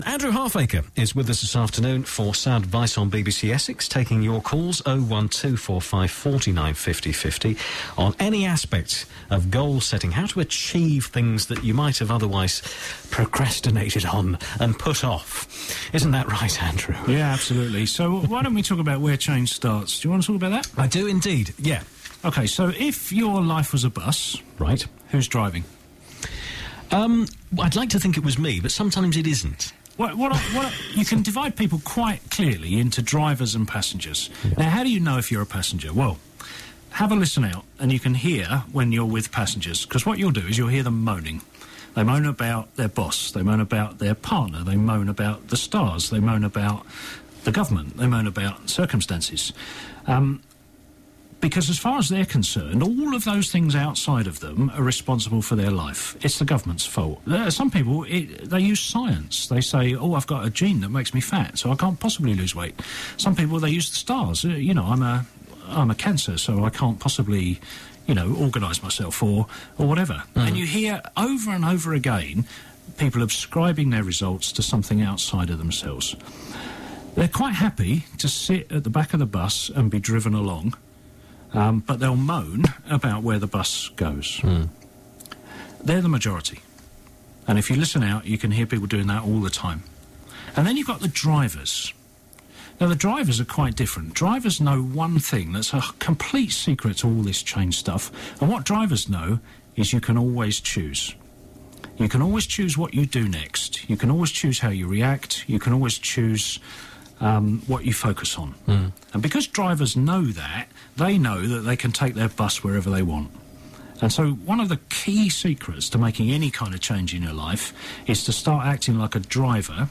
All the best bits from my Sound Advice slot on BBC Essex 24 Jan 2012.